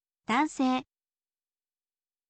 dansei